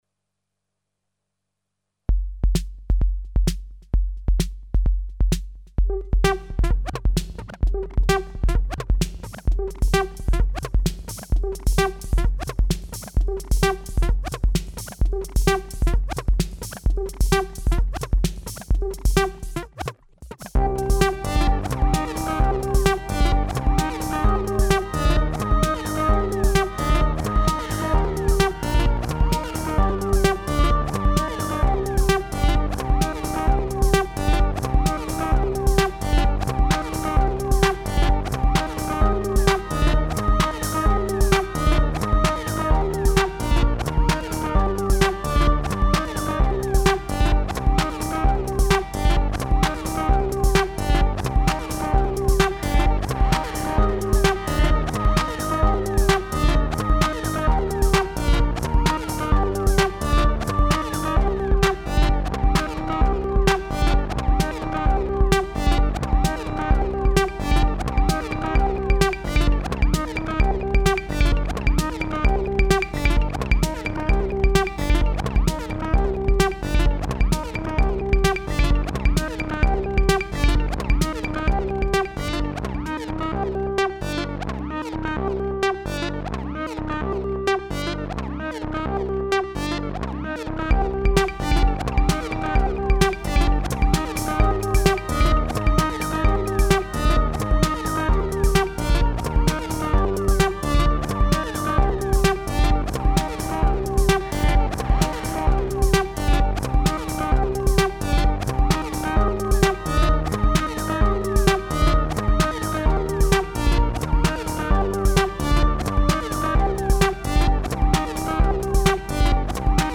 A4 + mfb 522
The 522 is a nice little analog drum box, I like mine :slight_smile:
Managed to get a seat with electrical outlets on the train back home from work, so I did a recording of yesterday’s pattern:
Nothing special, but at least it shows what a portable duo this is (a double seat, two electrical outlets and a couple of batteries in the H4n…).
nice! I like the sound.